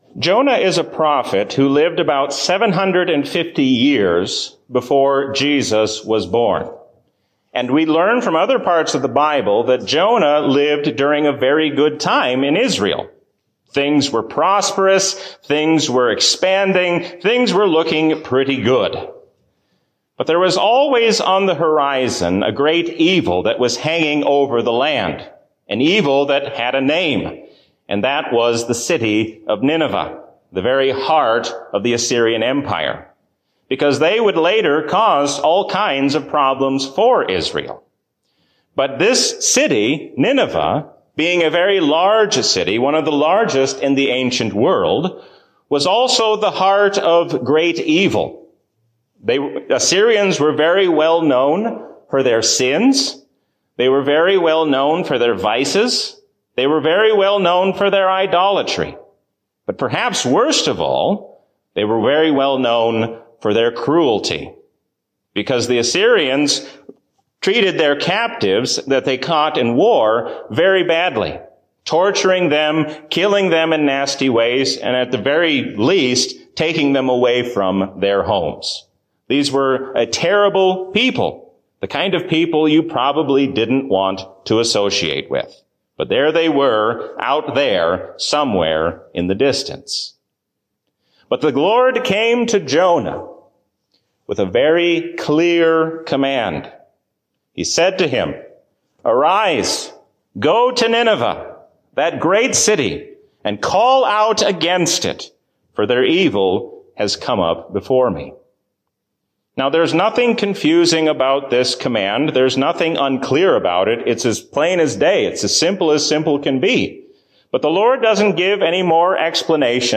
A sermon from the season "Easter 2021." We can rejoice because the Lord tells us how all things will end in His victory.